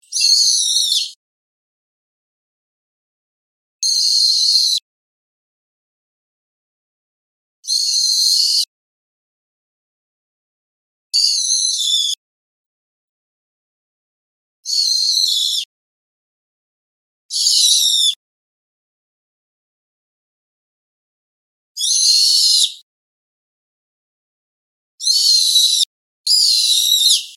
その際には、長い鳴き声「チューリーリーリー」といった、滑らかな鳴き声を上げることもあります。